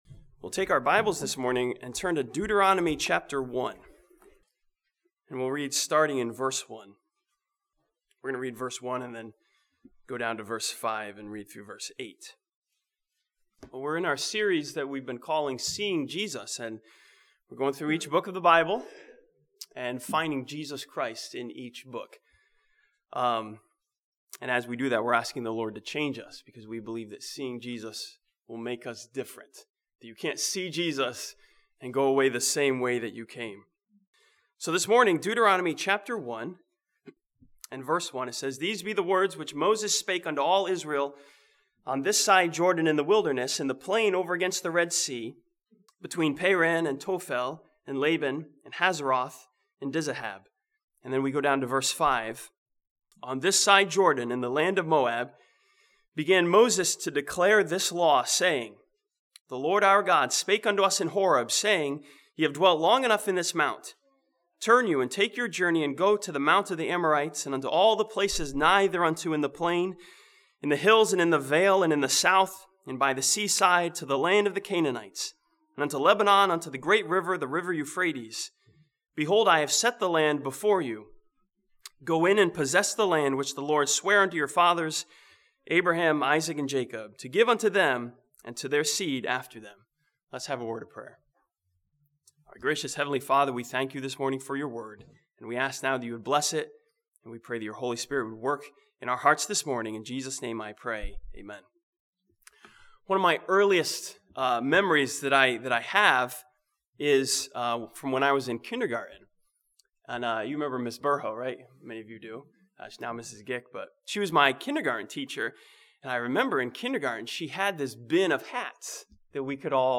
Sunday AM